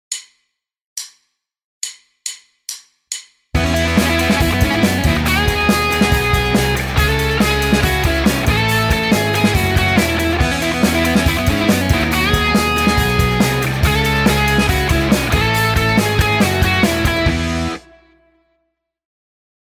5. Простота в лид-гитаре
Лид-гитара в брит-попе чаще всего строится на пентатонике и простом обыгрывании одних и тех же нот. Скорость здесь не играет большой роли, на первый план выходит простота и эффектность самой партии, оттеняющая некоторое однообразие звучания.